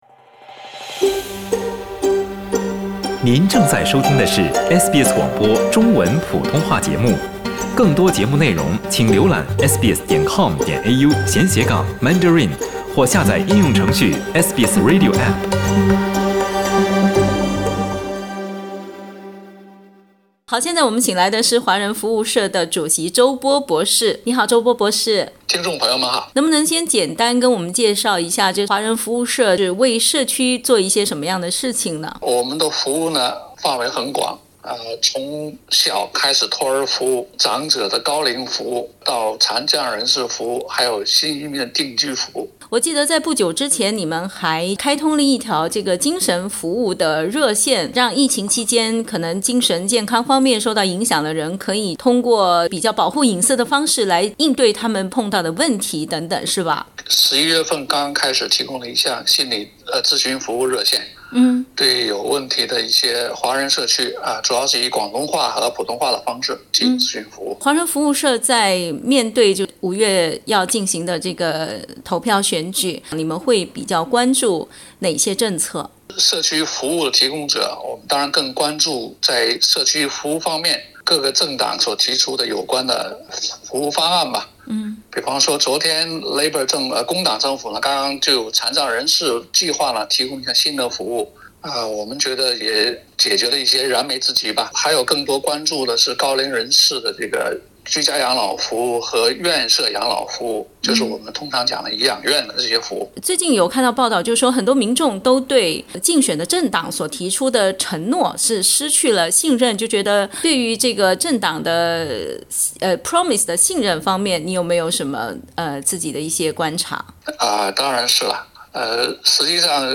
（点击图片收听完整采访） 澳大利亚人将于5月21日前投出选票，决定谁将领导这个国家。